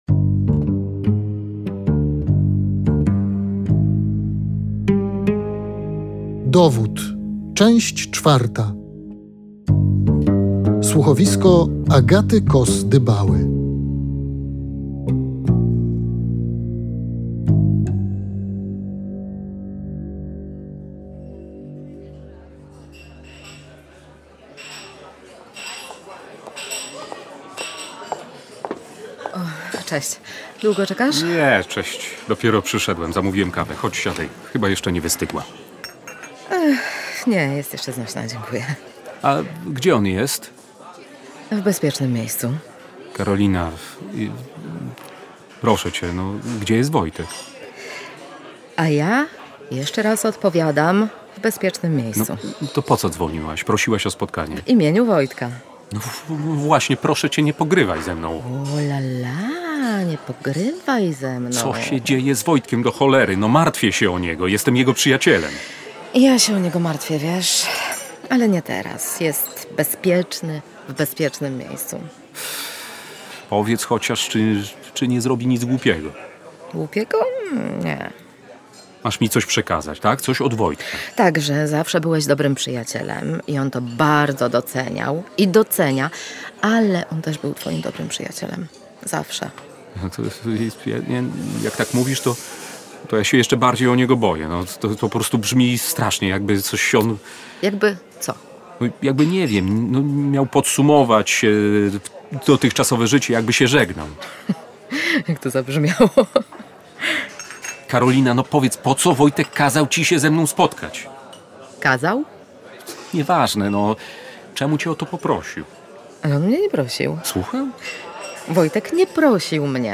Okaże się przede wszystkim, co w przeszłości łączyło bohaterów słuchowiska „Dowód” i jakie wynikły z tego konsekwencje. No i oczywiście, kto uprowadził i zabił nastolatkę.